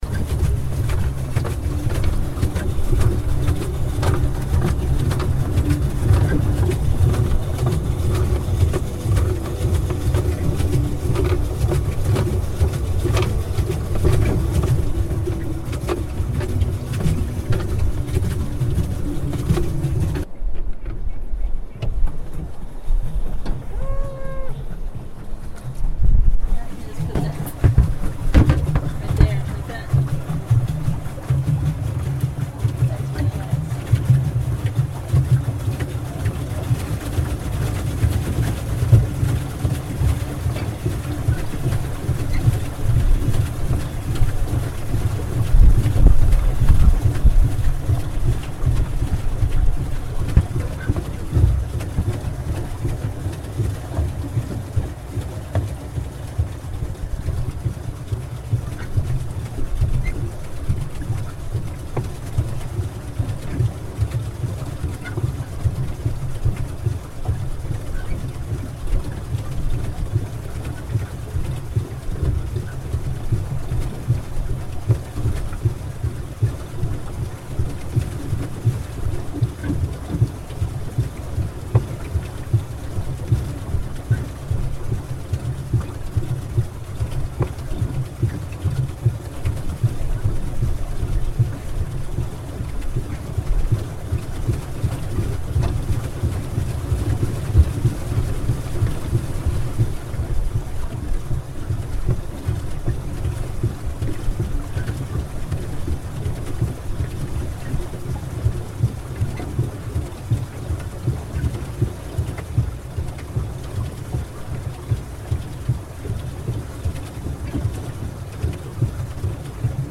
Pedal boating in Prague
On the Vltava in Prague